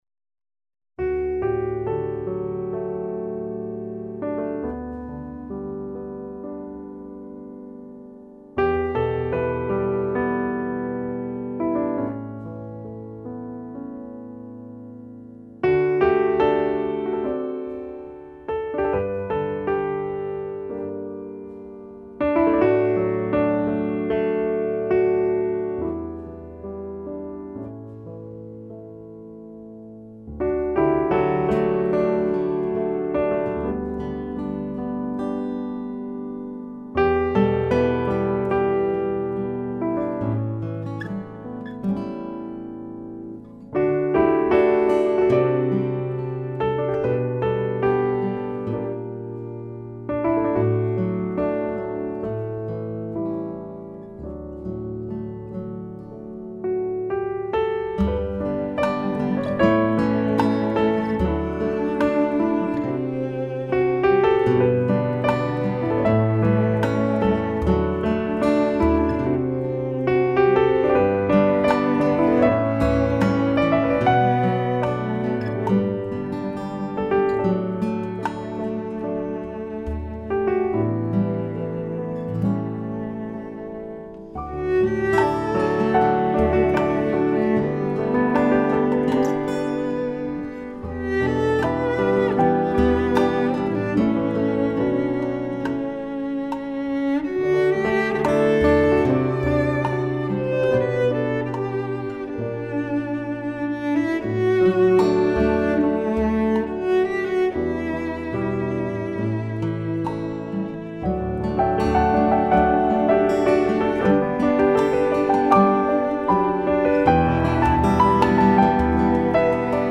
1995 Genre: New Age Lab
著名新世纪女钢琴艺人。
和许多女钢琴艺人仿似，虽然演绎作品的旋律简单，但是胜在细腻的感情表达和娴熟的演奏技巧。